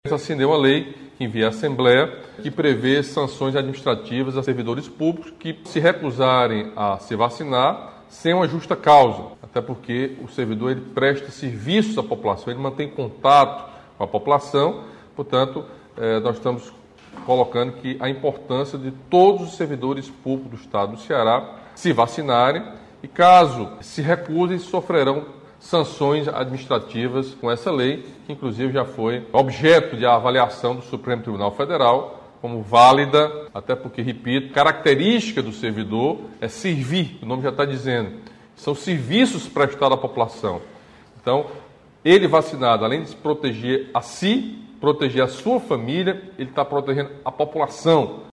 Durante a conversa com os internautas, o governador falou sobre o projeto de lei que prevê aplicação de sanções administrativas para servidores e empregados públicos estaduais que se recusarem a tomar vacina contra Covid-19.